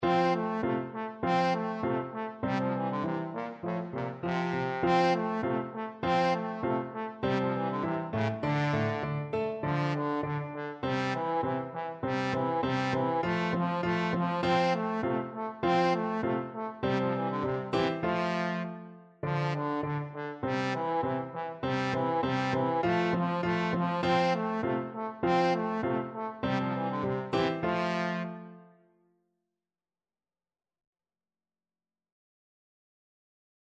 2/4 (View more 2/4 Music)
Allegretto
A3-C5
Classical (View more Classical Trombone Music)